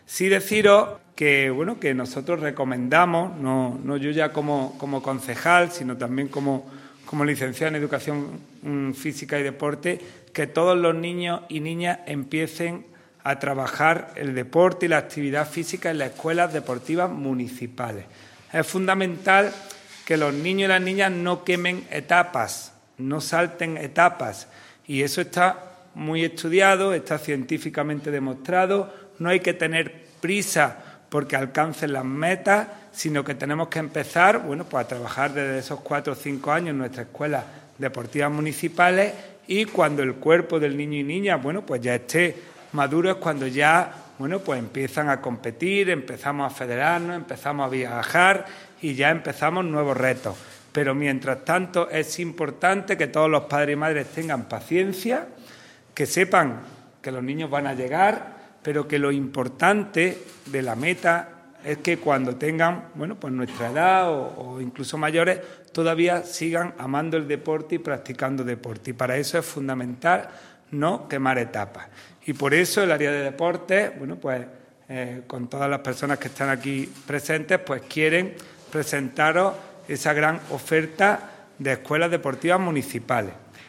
El teniente de alcalde delegado de Deportes, Juan Rosas, ha presentado hoy lunes en rueda de prensa el grueso de las escuelas deportivas municipales que compondrán la oferta al respecto del Área de Deportes del Ayuntamiento de Antequera.
Cortes de voz